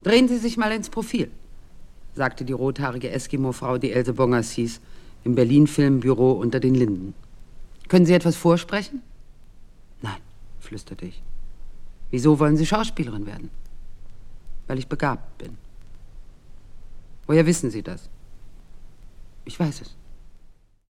Auf Anregung ihres Verlegers Fritz Molden legte Hildegard Knef auch eine Doppel-LP mit einigen gelesenen Kapiteln ihrer Autobiographie vor.